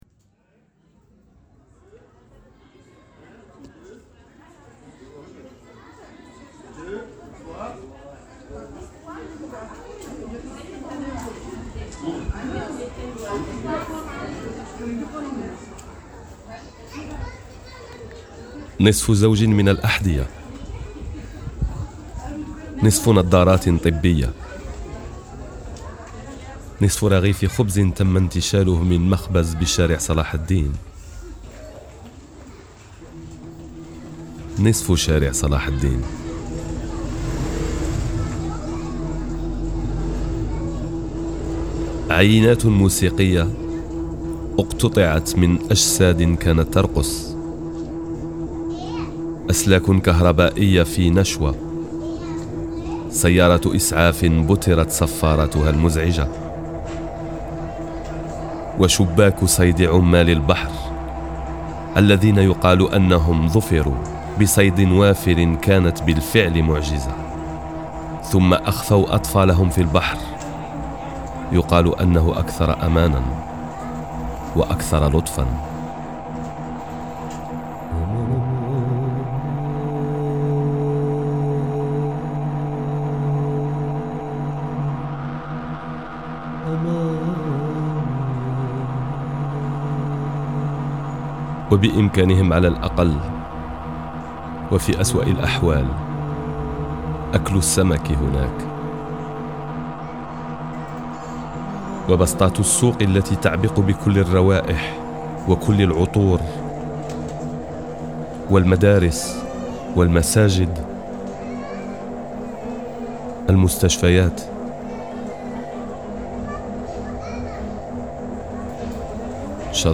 Lecture en français et en arabe, création musicale, enregistrement et mixage